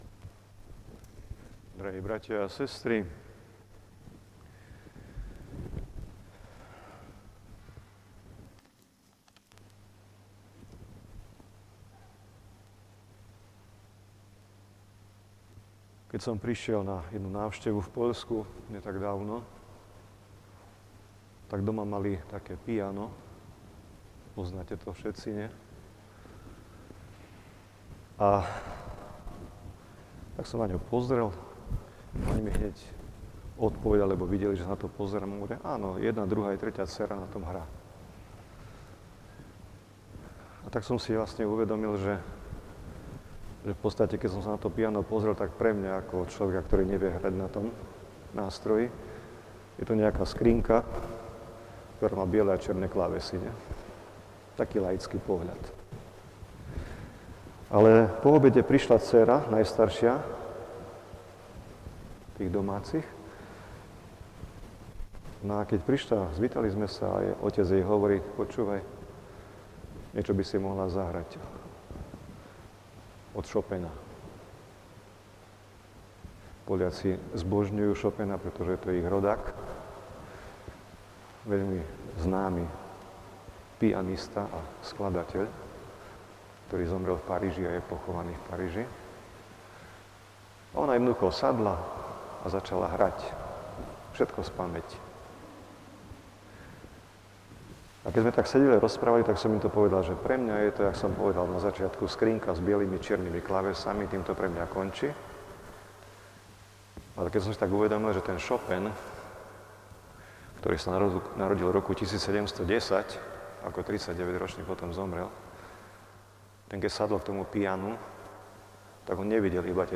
Na sviatok Kristovho narodenia sa ctitelia blahoslaveného Metoda Dominika Trčku stretli v bazilike, aby sa spolu modlili pri jeho relikviách.